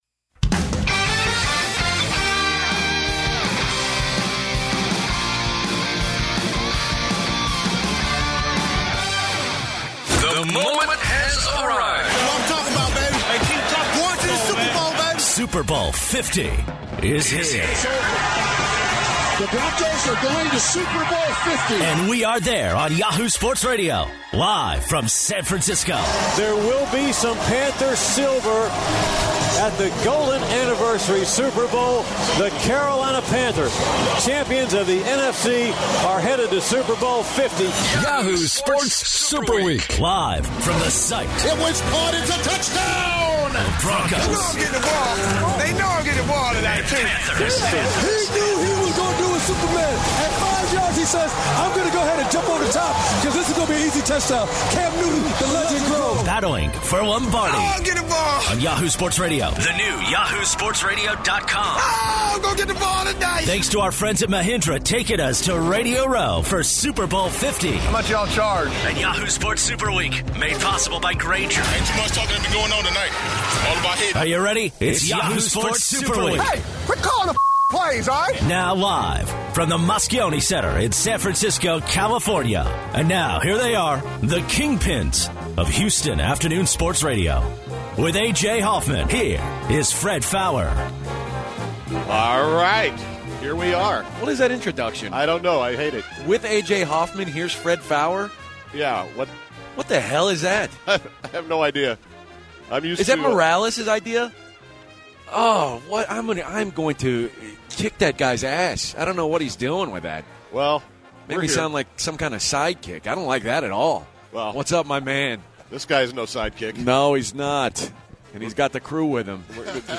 The Blitz started off with an interview with the President of the UFC, Dana White. The guys got to talk with Solomon Wilcots and Antwon Blake, the cornerback with the Pittsburgh Steelers, about their thoughts on the Super Bowl. The guys finished the hour speaking with Danny Kanell about college football and his opinion about the Panthers and Broncos for Sunday’s game.